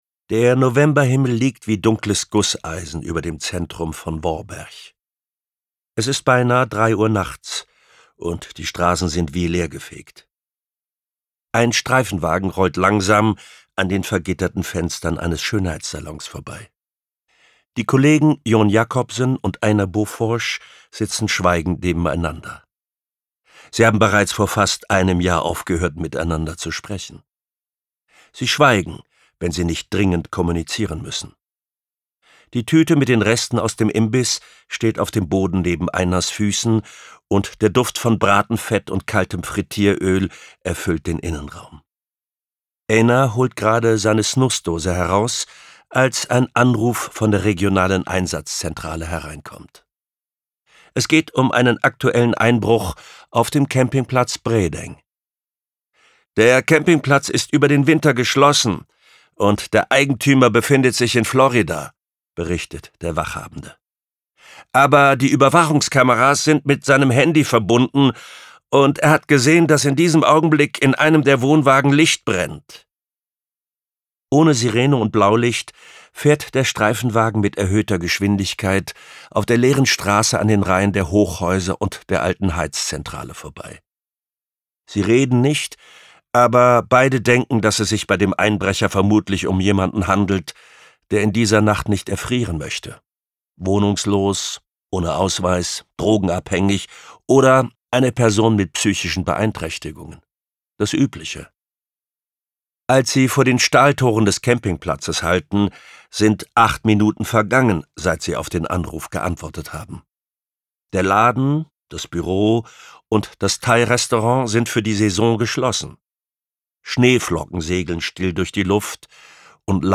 Gekürzte Lesung